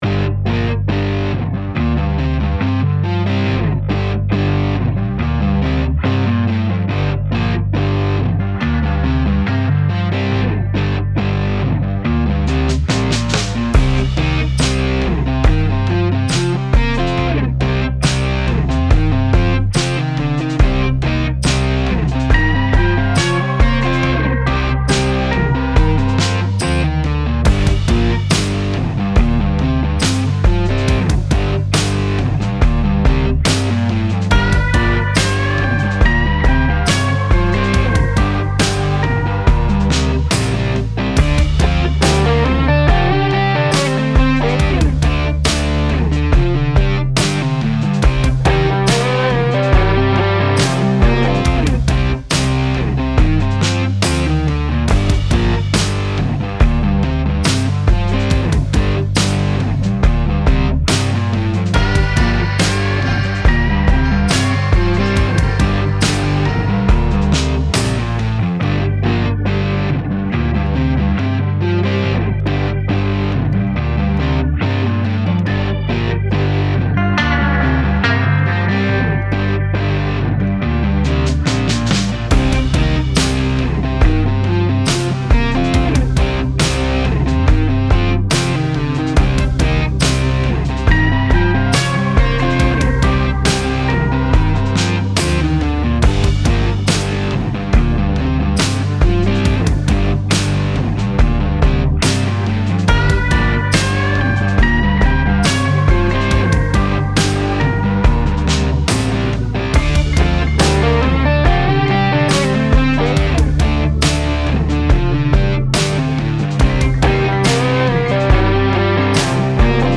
Style Style Country, Rock
Mood Mood Dark, Driving, Relaxed
Featured Featured Bass, Drums, Electric Guitar +2 more
BPM BPM 70